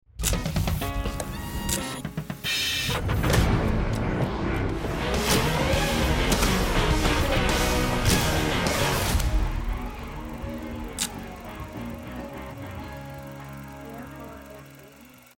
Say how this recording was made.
Tour rehearsals!